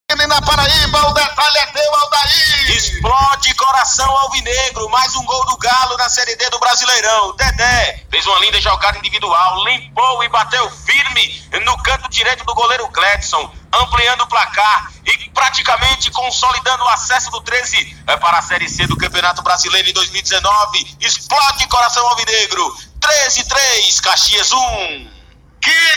Ouça a emoção no gol da vitória do Galo da Borborema…